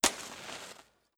MatchStrike.wav